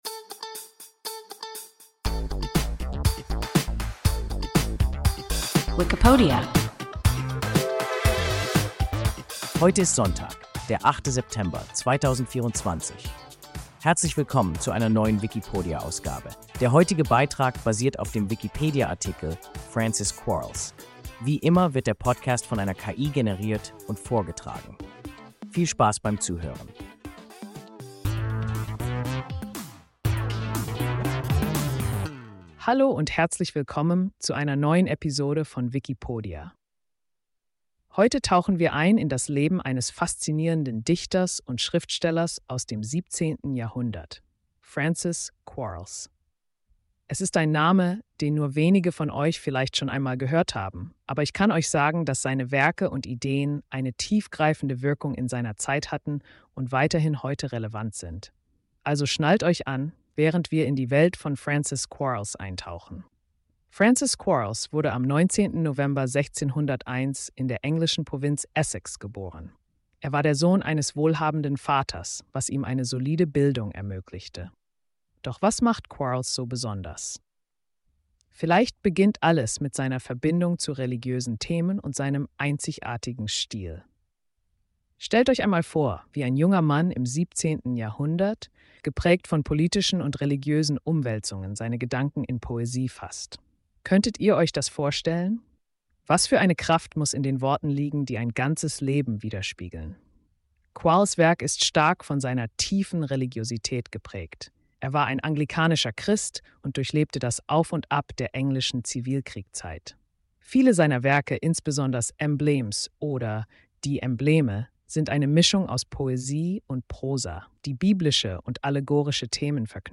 Francis Quarles – WIKIPODIA – ein KI Podcast